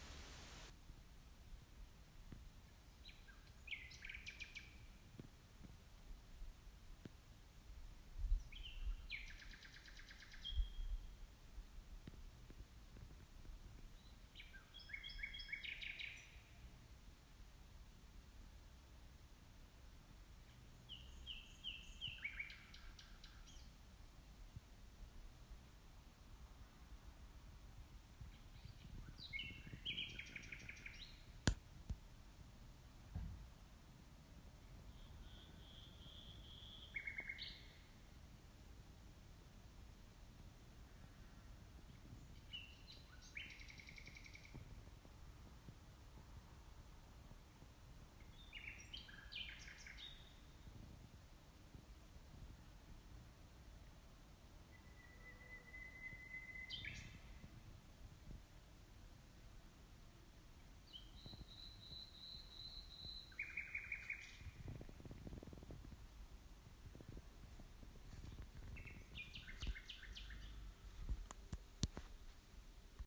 Punctual as ever, I heard the nightingale last night for the first time this year. Some phrases of his tune warble, some stutter, some notes are drawn out as if longing for his mate. It’s a truly magical sound and a happy birthday to my daughter.
nightingale-2.m4a